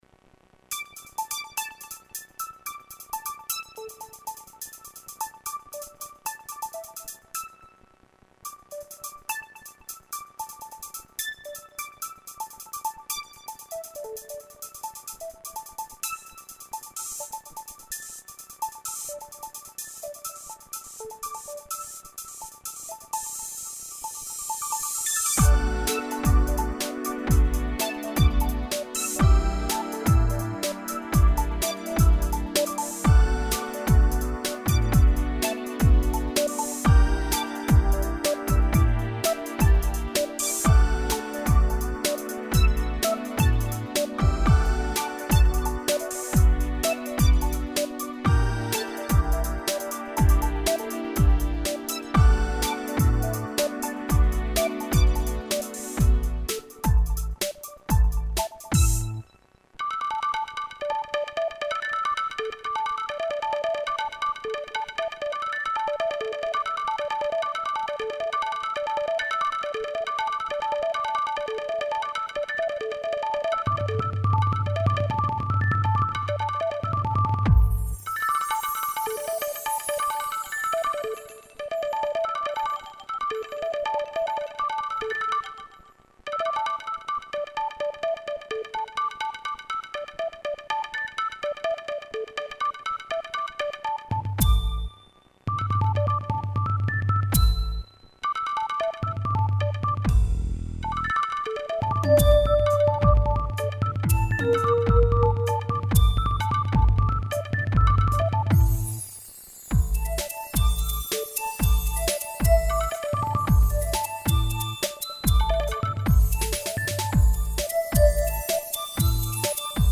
Perdonatemi quindi se c'è un po' di rumore di fondo, così come qualche errore di esecuzione (ma è bello così! Non vorrete certo ascoltare una batteria artificiale o dei pezzi ritoccati!)
In questo pezzo ci sono innumerevoli strumenti dai suoni dolci: una batteria elettronica base, tastiere, 3 bassi, glockenspiel, marimba, tubular bells, legnetti, crotales, Xylophone... e pure qualche effetto elettronico.
Diverse scale di note accompagnano tutti gli strumenti dal charleston ai piatti, dalla cassa ai tom.
E' dolcissimo! E davvero curioso!